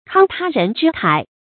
慷他人之慨 kāng tā rén zhī kǎi
慷他人之慨发音
成语注音 ㄎㄤ ㄊㄚ ㄖㄣˊ ㄓㄧ ㄎㄞˇ